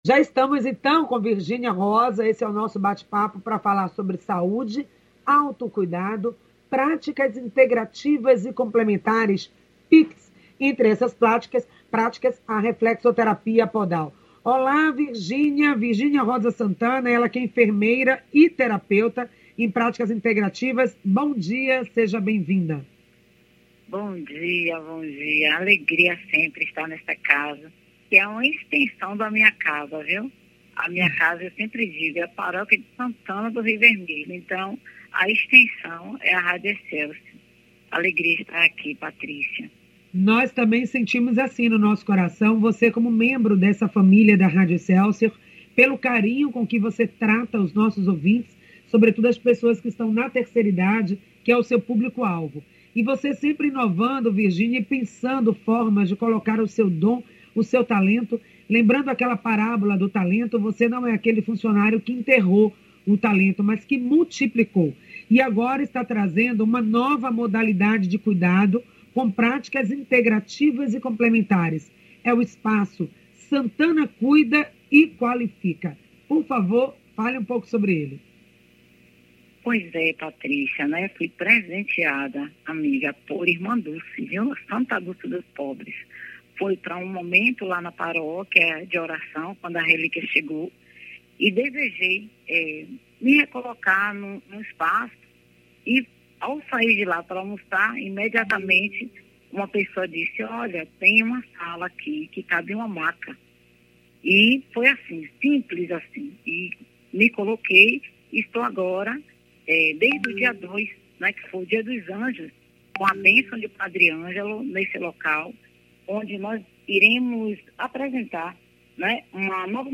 O programa Excelsior Saúde, acontece das 9h às 10h, ao vivo com transmissão pela Rádio Excelsior AM 840.